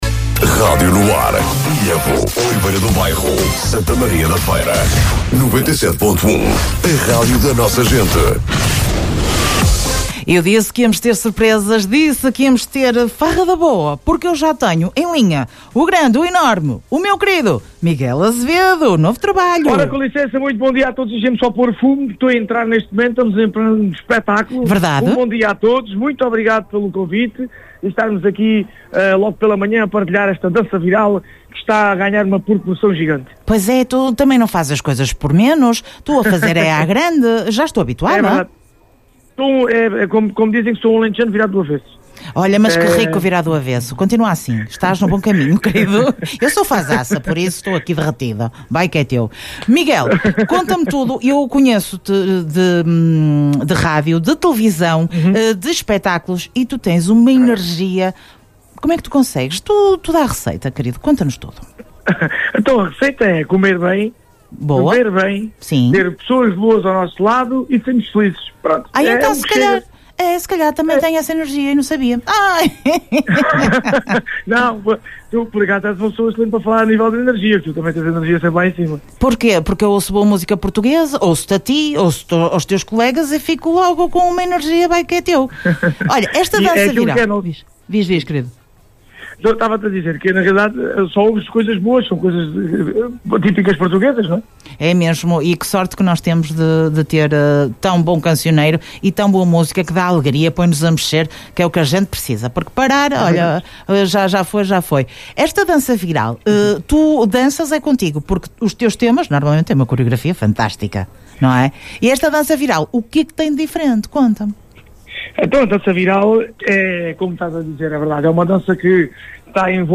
Entrevista
em direto nas “Manhãs NoAr”